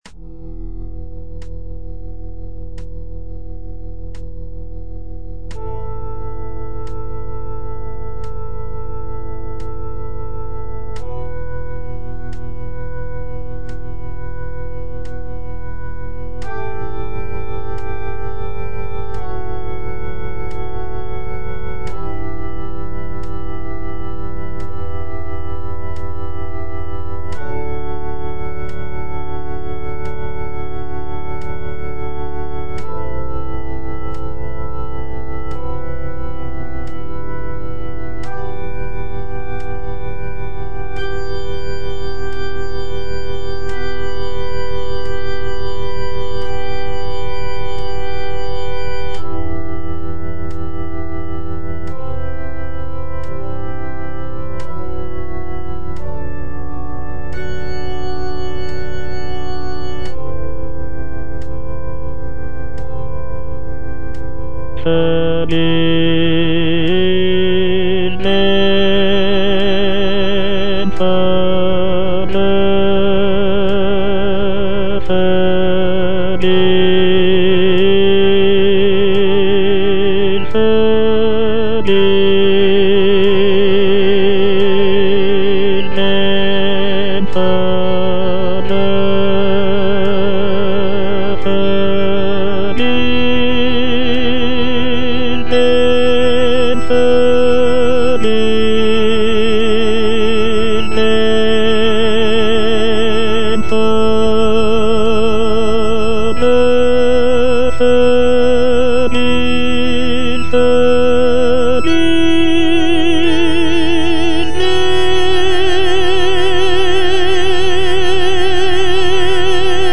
tenor II) (Voice with metronome